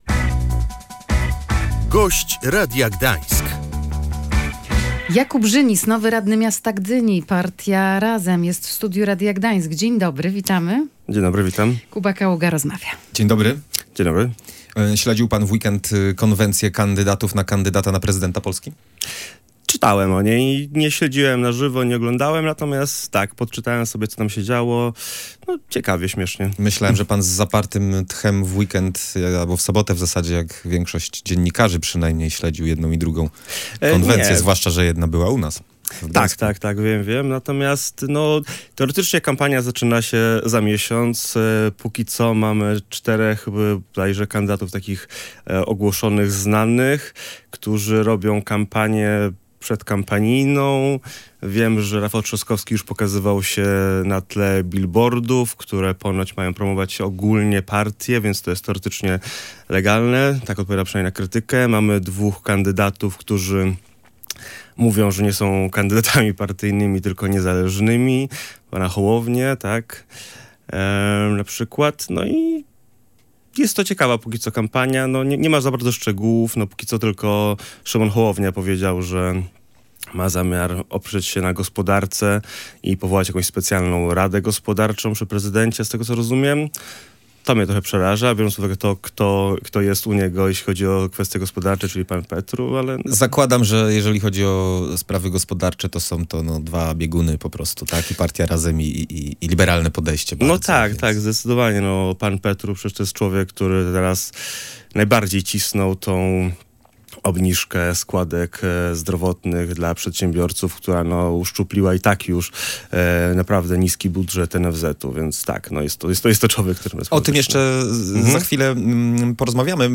Tylko kandydat Lewicy zapewni Polakom bezpieczeństwo w kwestiach społecznych czy zdrowotnych – mówił w Radiu Gdańsk Jakub Żynis, nowy radny miasta Gdyni z Partii Razem. Lewica ma wystawić swojego kandydata na prezydenta za tydzień.